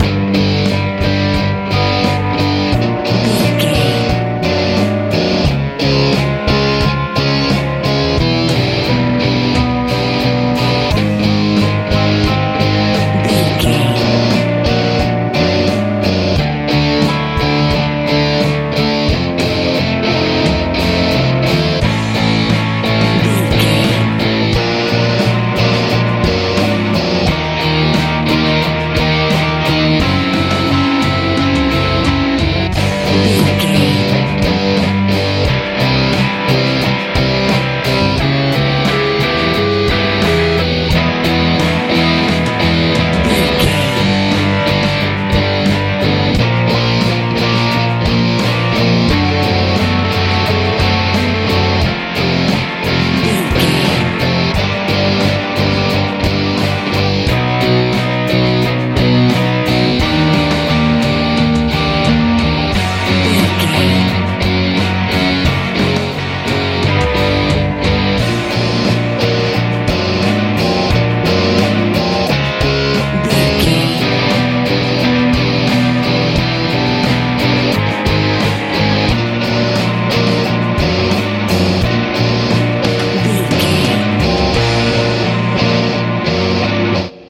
sludge mental feel
Ionian/Major
D
driving
bass guitar
drums
electric guitar
heavy